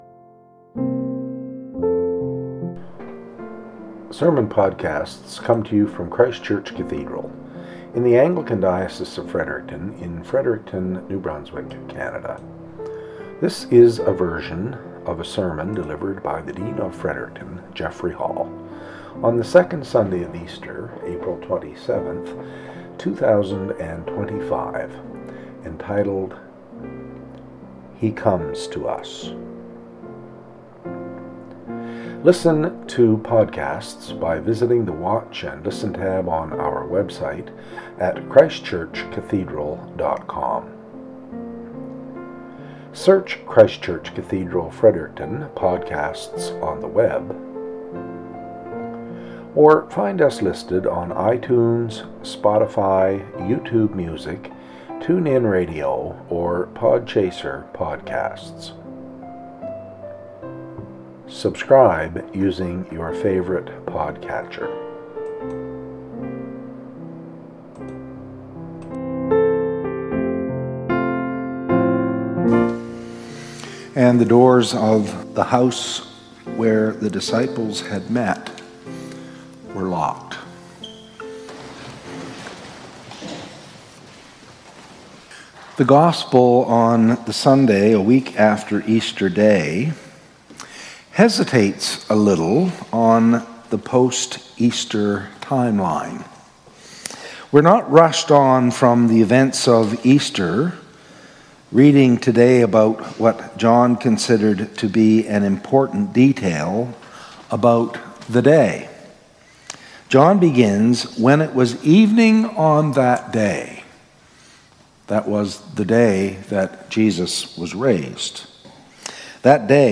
SERMON - "He Comes to Us"